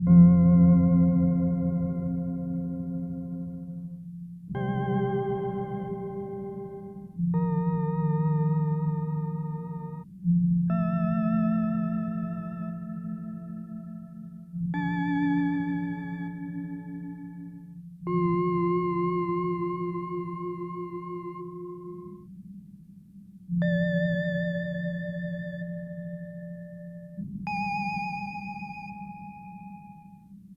All_Piano_Keys.ogg